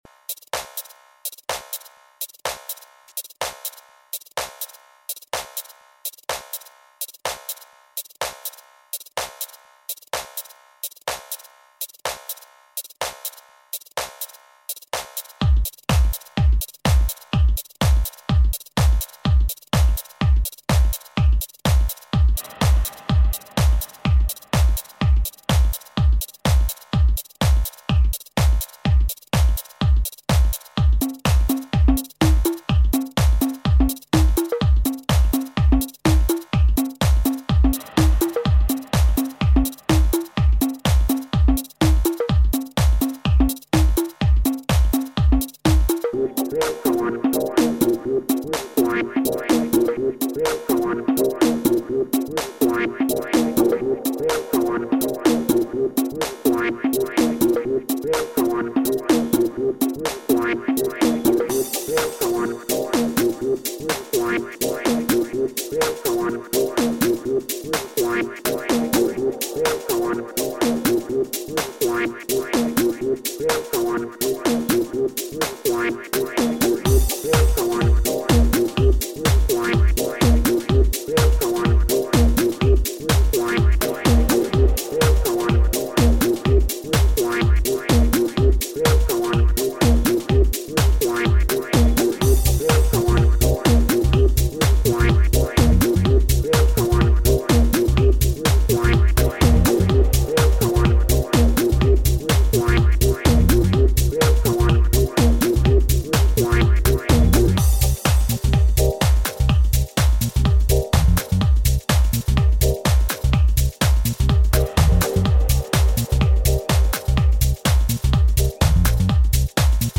The Kangaroo Das passiert wenn man mit seinem apfeligen Smartphone Musik macht